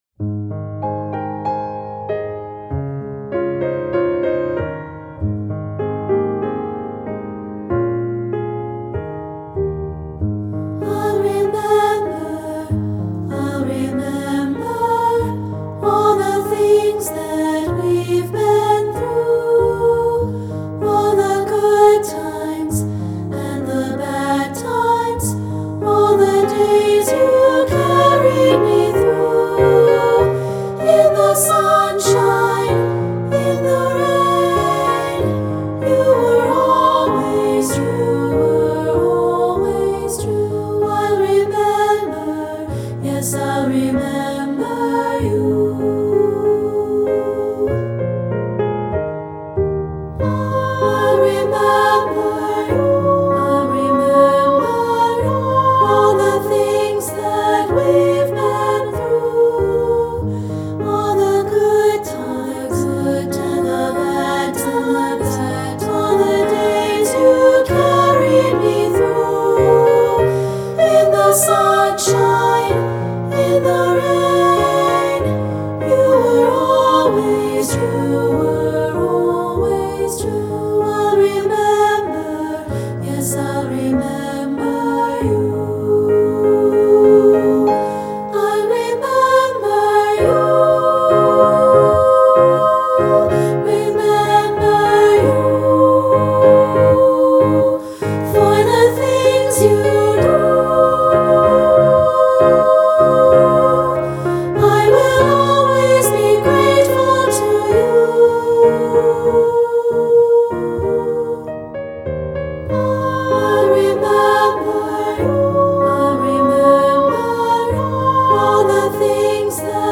Choral Concert/General Graduation/Inspirational